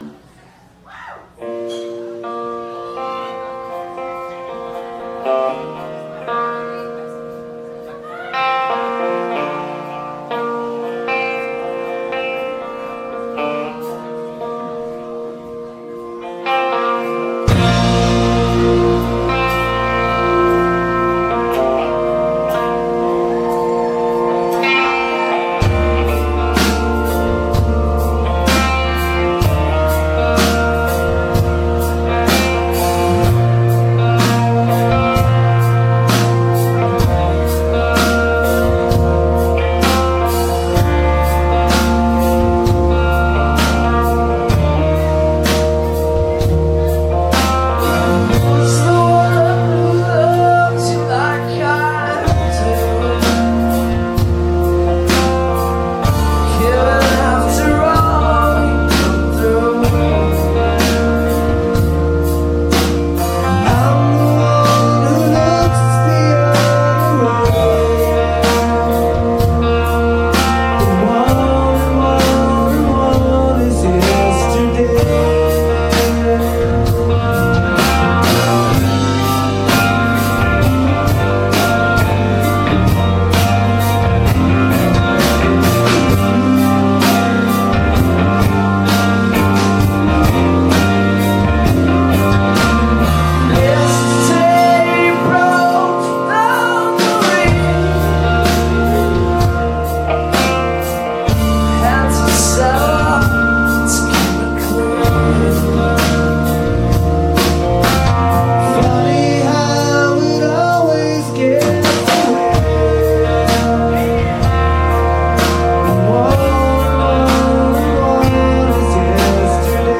the casbah san diego september 17 2000